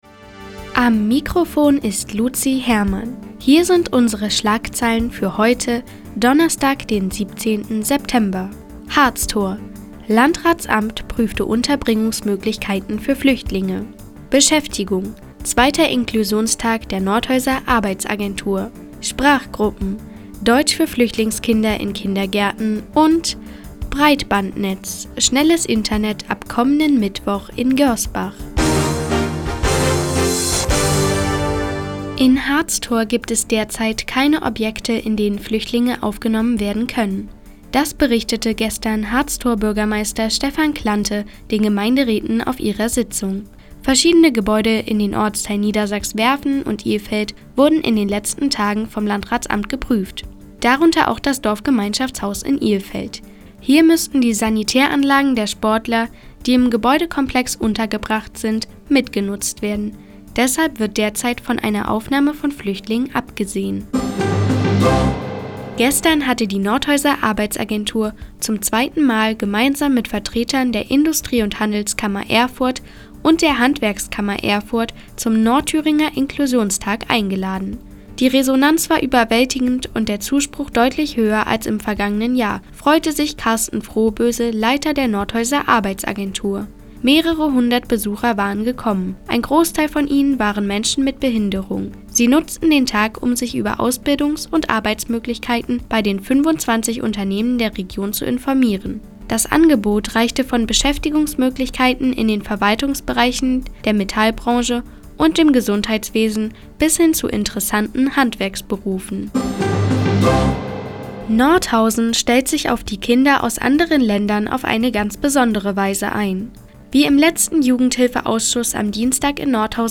Do, 17:00 Uhr 17.09.2015 Neues vom Offenen Kanal Nordhausen „Der Tag auf die Ohren“ Seit Jahren kooperieren die Nordthüringer Online-Zeitungen, und der Offene Kanal Nordhausen. Die tägliche Nachrichtensendung des OKN ist jetzt hier zu hören.